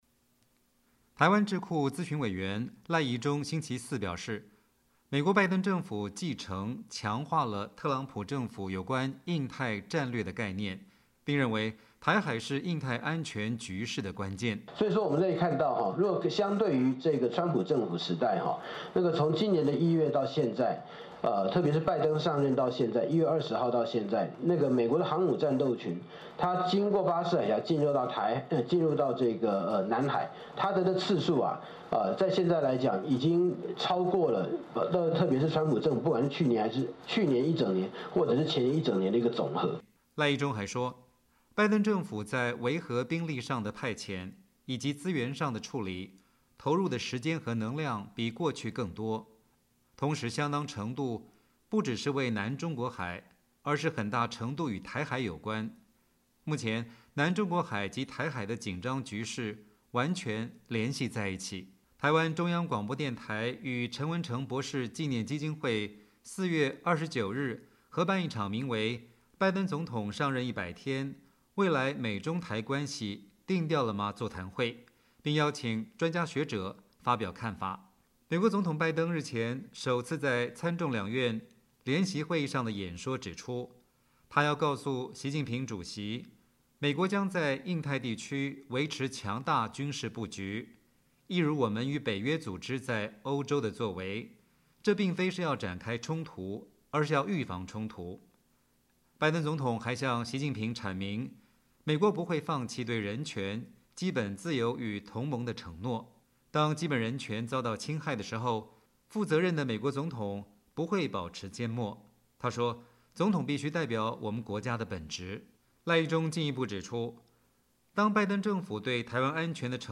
台湾中央广播电台与陈文成博士纪念基金会4月29日合办一场名为“拜登总统上任100天，未来美中台关系定调了吗”座谈会，并邀请专家学者发表看法。